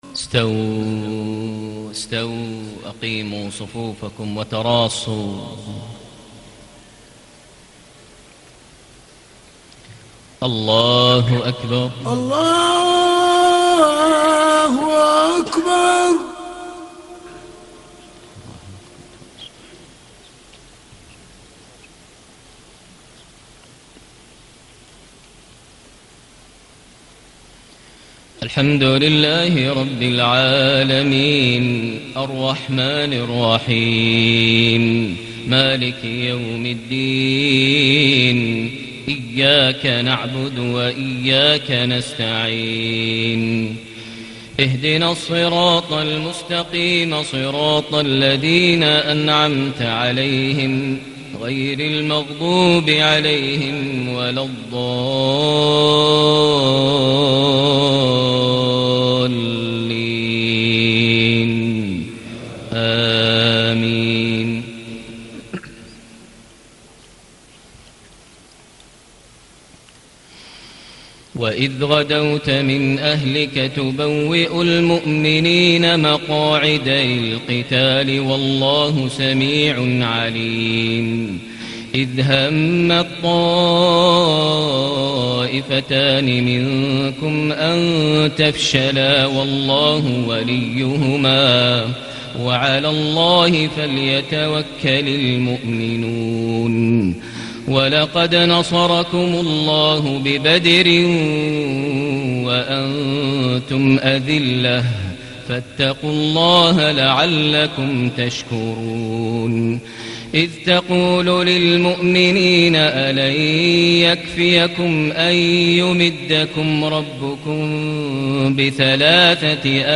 صلاة العشاء ٢٩ صفر ١٤٣٨هـ سورة آل عمران ١٢١-١٣٦ > 1438 هـ > الفروض - تلاوات ماهر المعيقلي